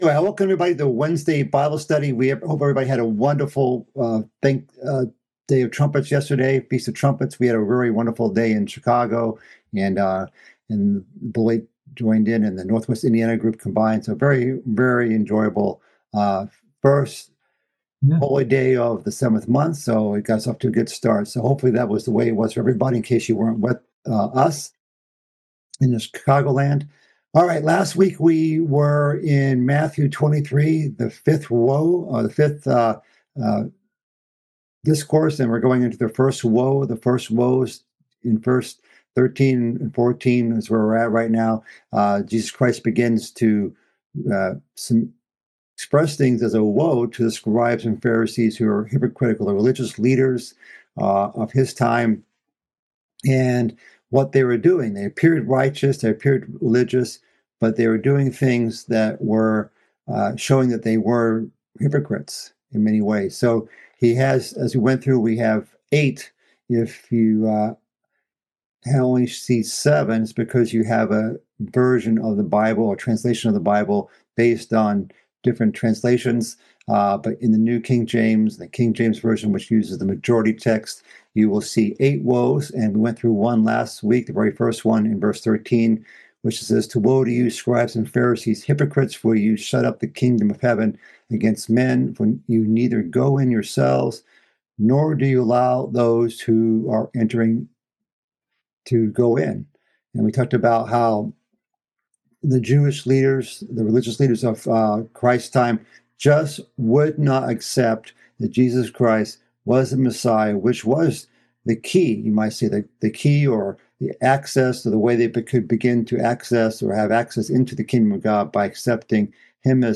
This is the fourth part of a mid-week Bible study series covering Christ's fifth discourse in the book of Matthew. It covers woes to the scribes and Pharisees for a variety of things, including: oppressing widows, making long prayers to seem righteous, teaching their manmade doctrine to proselytes, swearing by various holy things, and neglecting the weightier matters of the law.